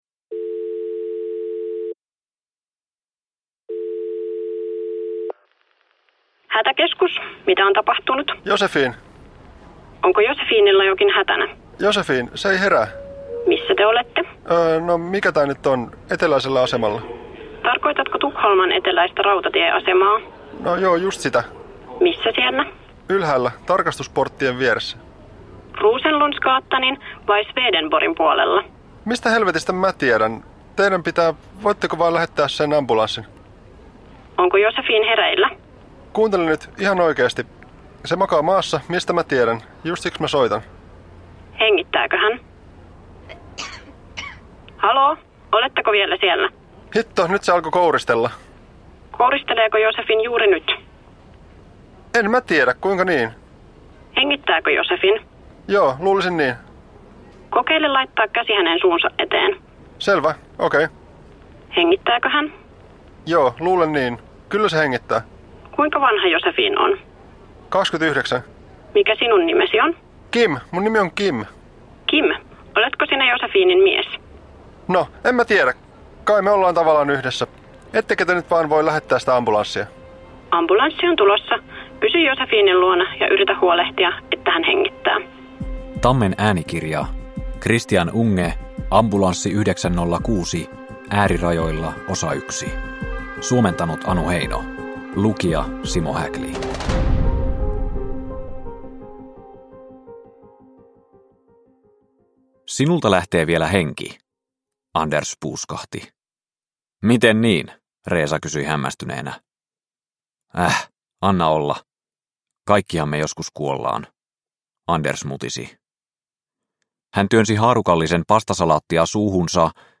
Ambulanssi 906 Osa 1 – Ljudbok – Laddas ner